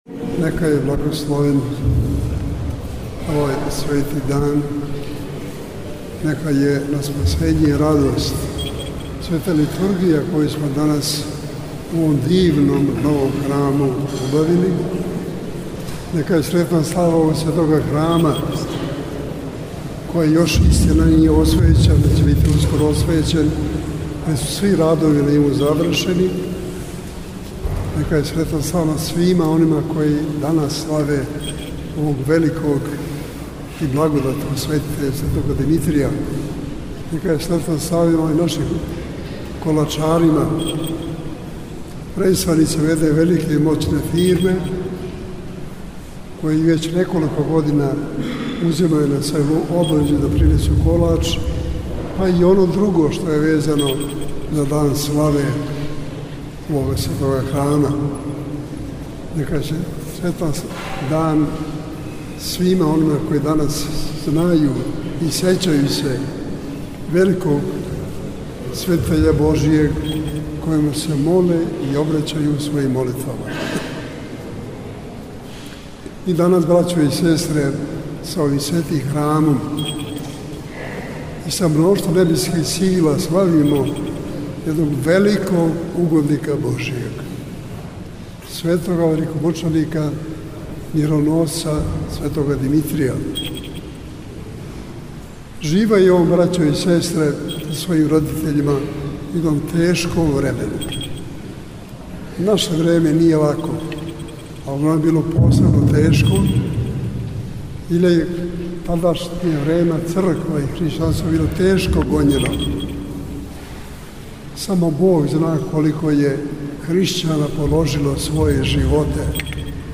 Његова Светост Патријарх српски г. Иринеј служиo je на празник Светог великомученика Димитрија у Храму посвећеном овом Божјем угоднику на Новом Београду.